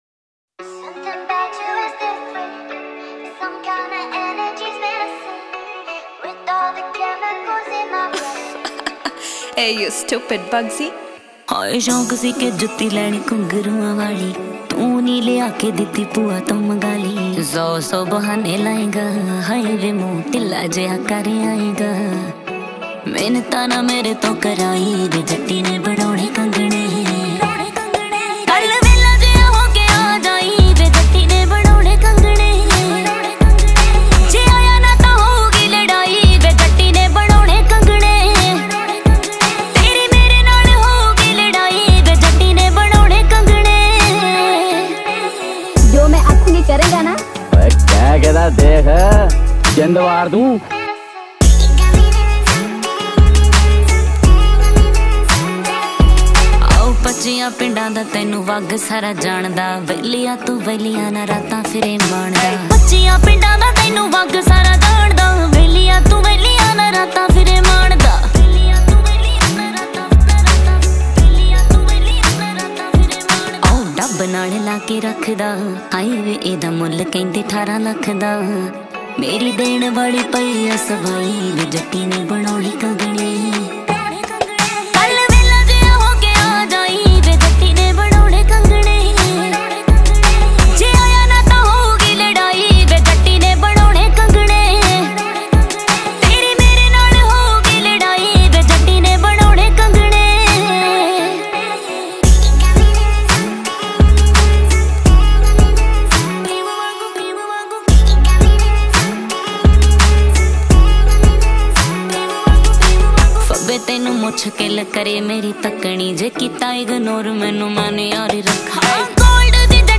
Punjabi Songs